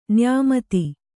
♪ nyāmati